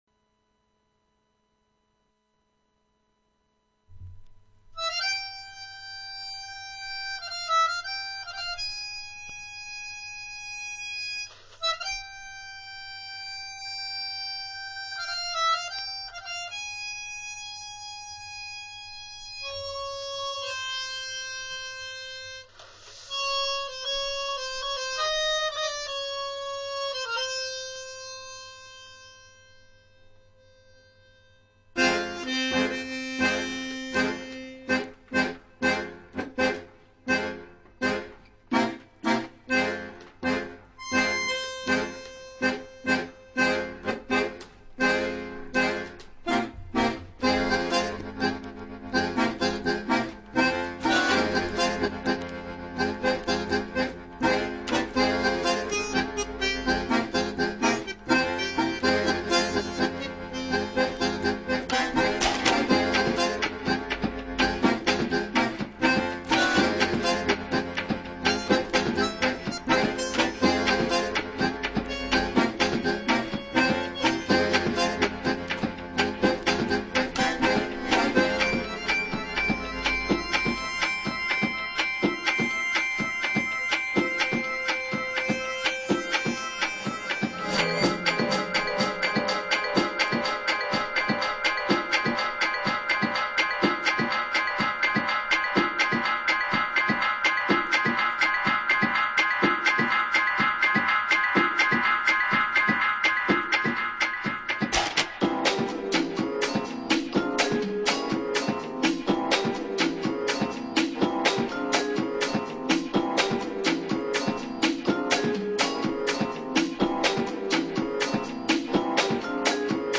How funky can it possibly get?